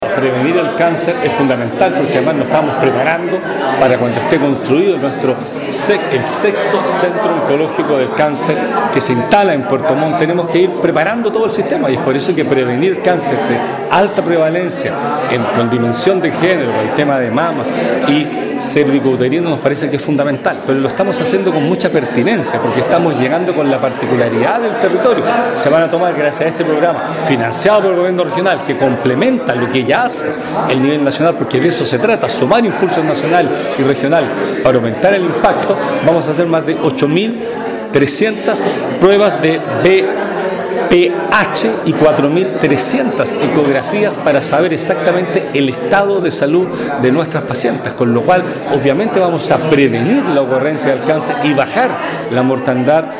El Gobernador de la región de Los Lagos, Patricio Vallespin, enfatizó que prevenir el cáncer es fundamental, principalmente en aquellos de alta prevalencia, con dimensión de género, como es el cáncer de mamas y cérvico uterino.